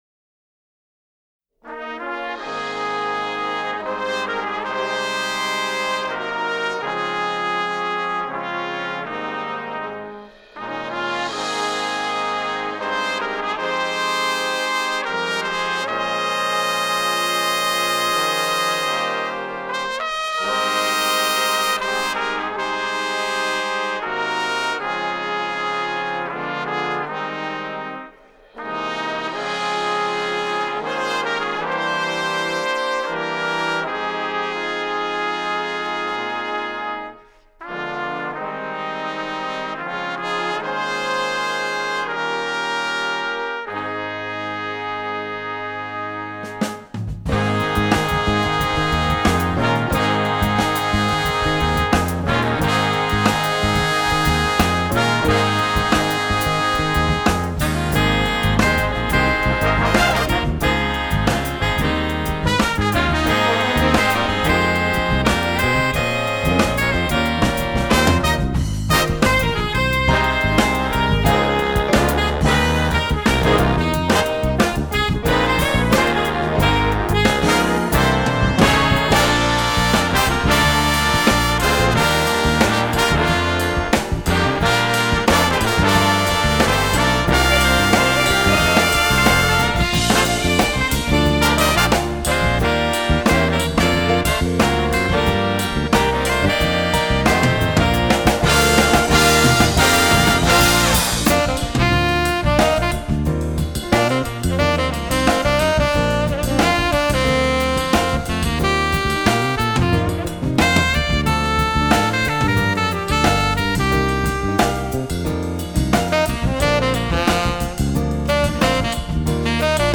Composer: Traditional
Voicing: Jazz Ensemble